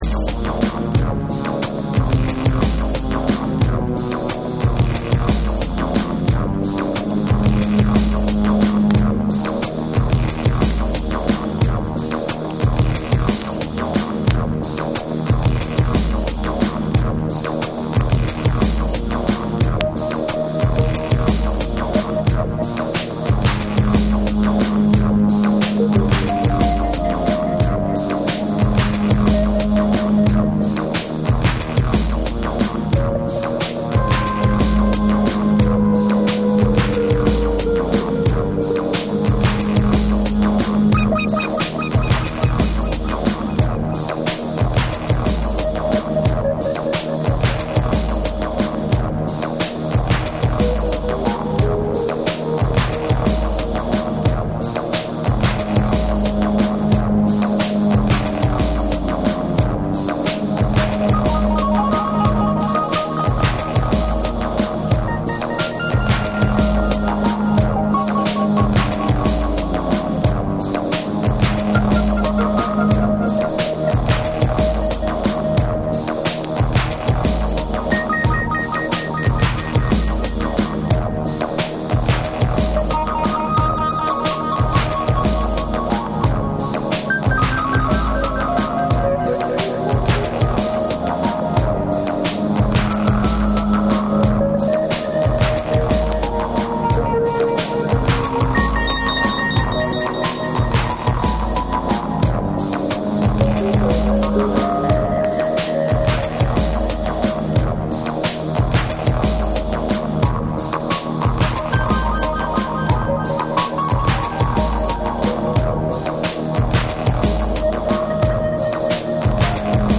混沌とした空気感とエレクトロニクスが融合した実験性の高いナンバーを収録。
BREAKBEATS